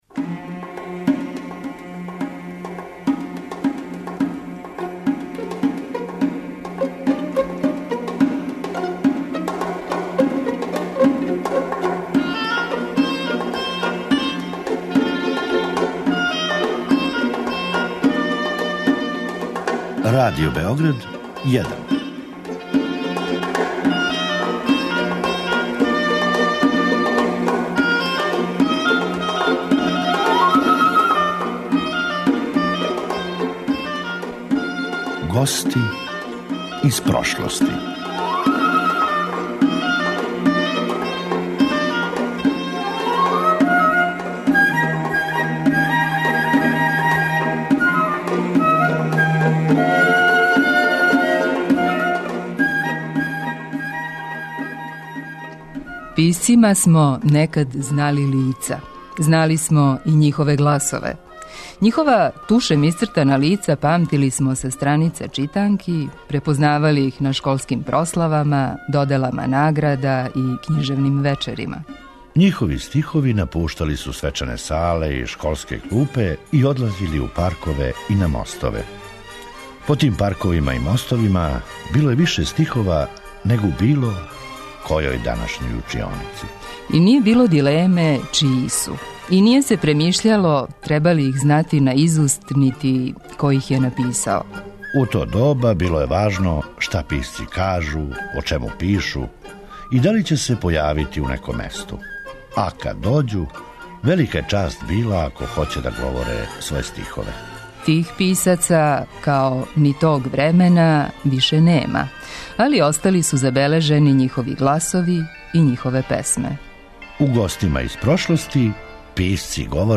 Тих писаца, као ни тог времена, више нема, али остали су забележени њихови гласови и њихове песме.
У "Гостима из прошлости" - писци говоре своје стихове.